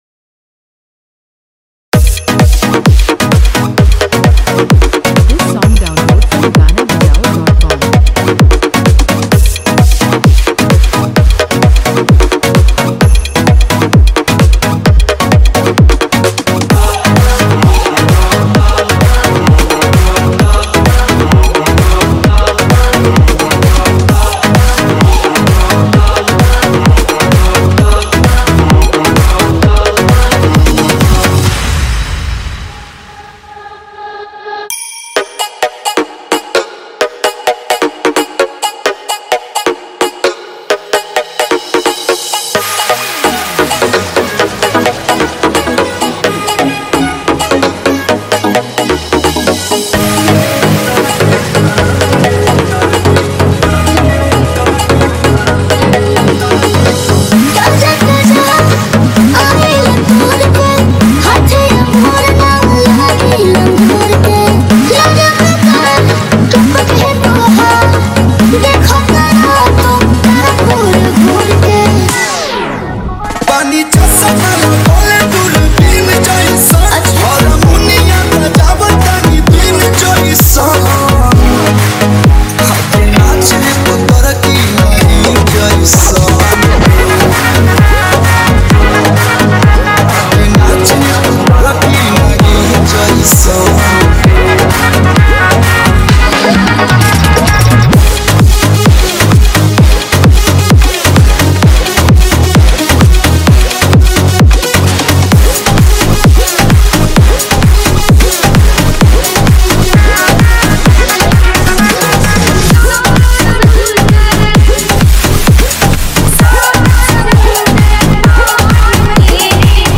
Disco Dance Remix